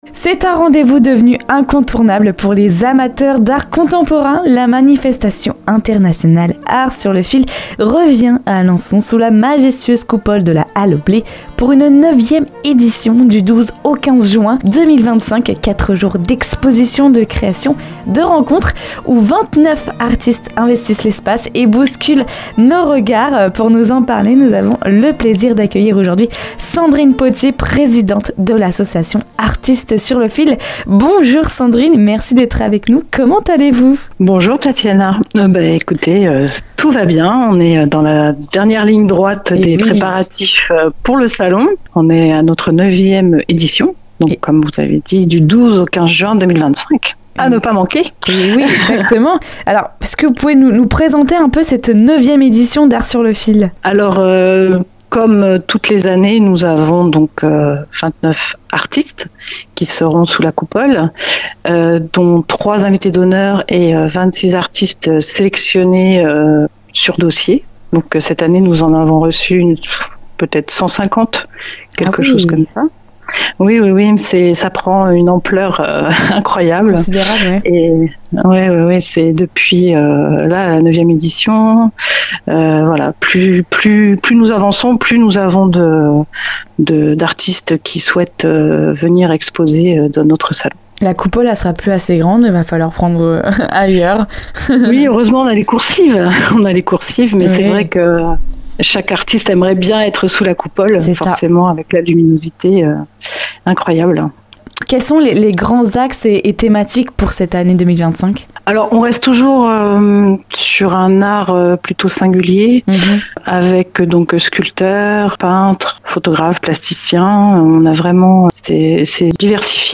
culture local art salon